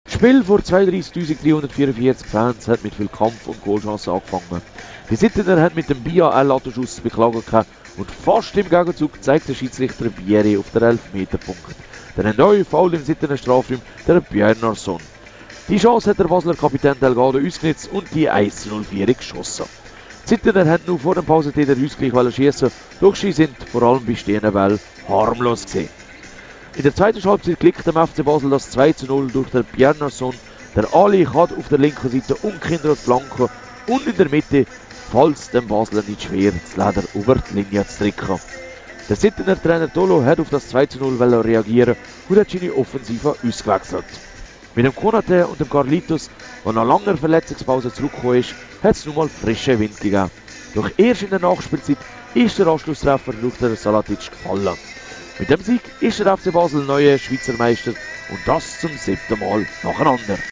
sl Matchbericht FC Basel